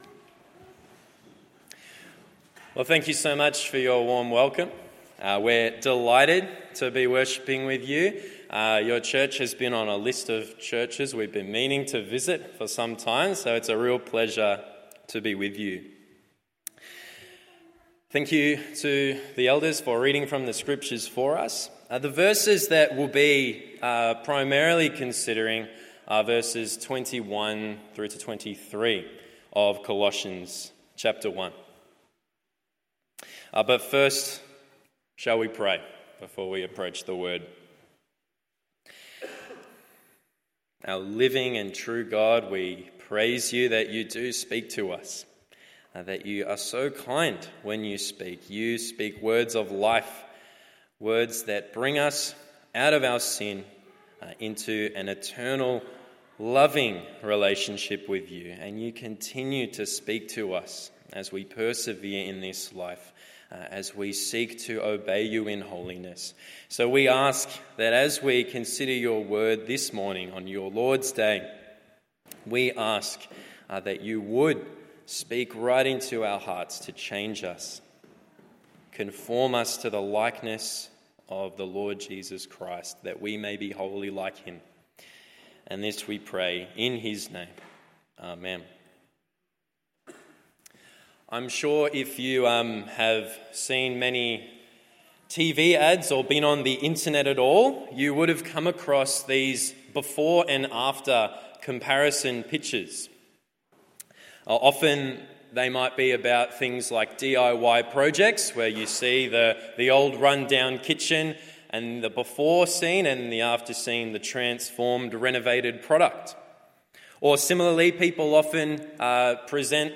Morning Service Colossians 1:13-23…